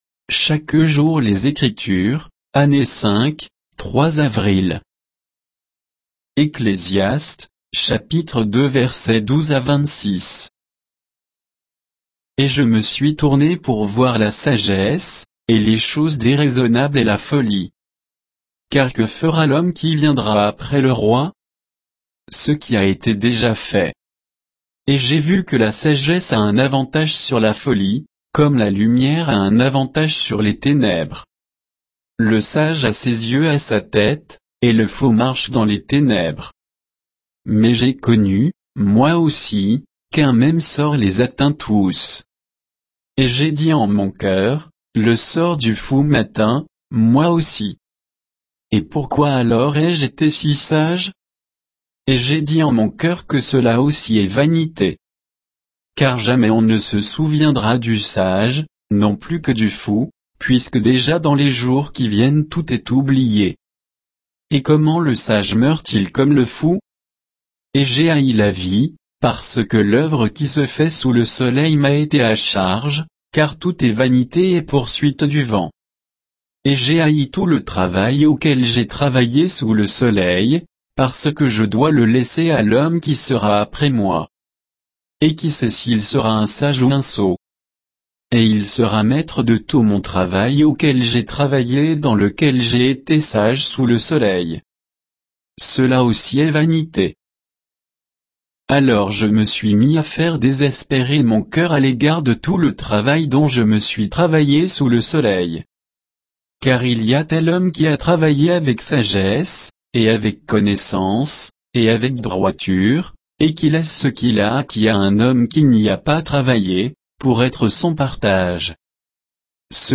Méditation quoditienne de Chaque jour les Écritures sur Ecclésiaste 2